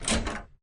door.opus